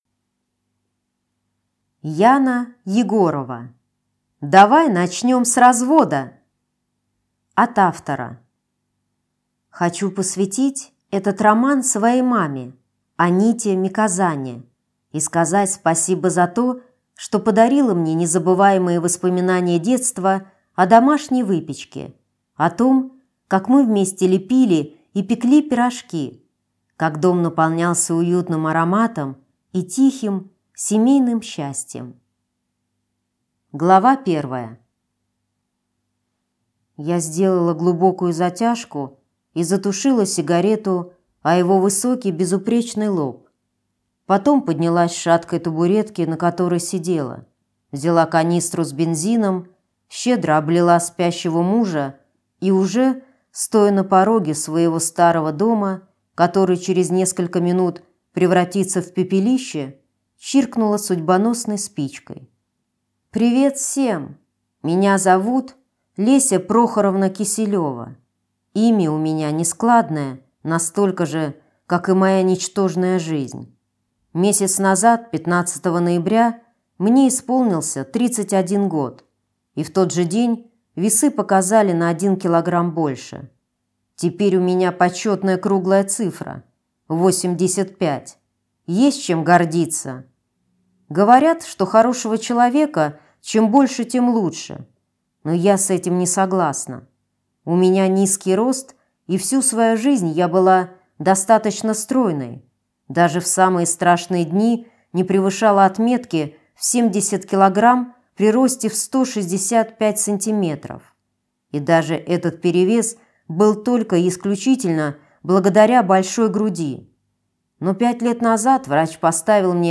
Aудиокнига Давай начнем с развода!